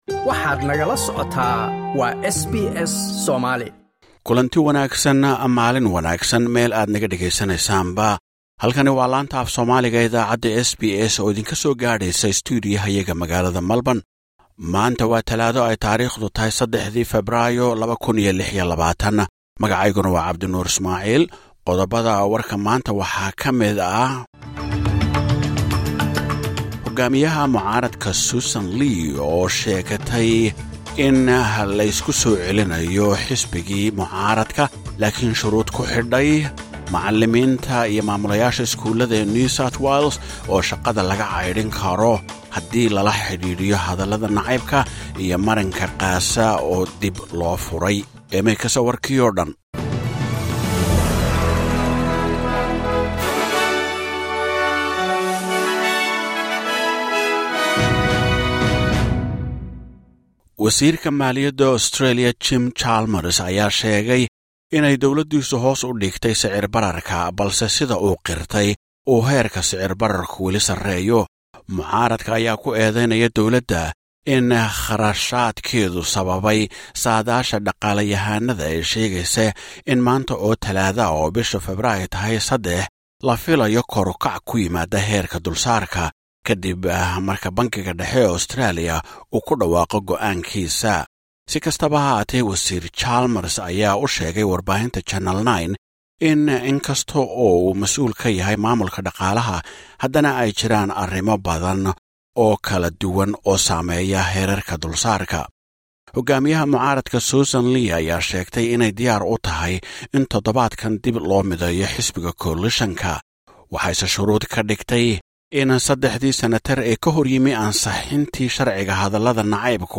SBS Somali News: Warka Talaado 3 February 2026